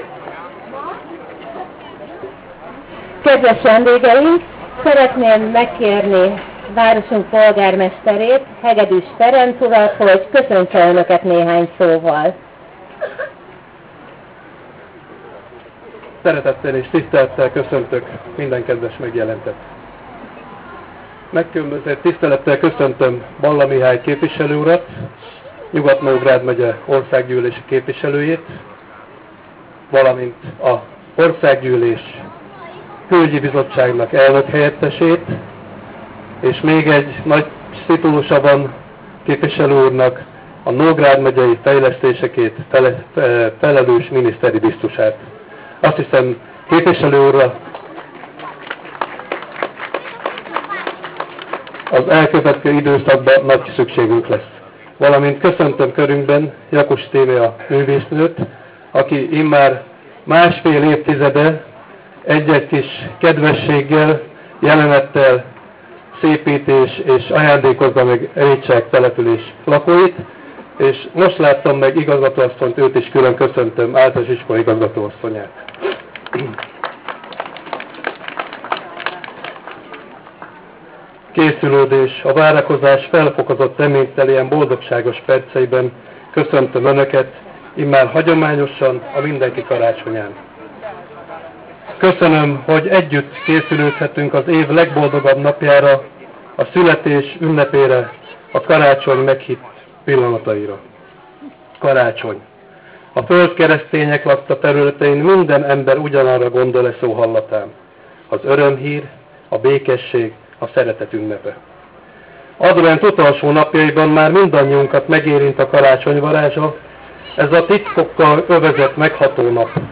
Az m�vel�d�si h�z �p�lete el�tt folytat�dott a program, ahol egy feld�sz�tett feny�fa �ll, jelk�pezve a mindenki kar�csonyf�j�t. Heged�s Ferenc polg�rmester �s Balla Mih�ny orsz�ggy�l�si k�pvisel� mondott k�sz�nt�t. (Ezek meghallgathat�k az alul is elhelyezett linken.)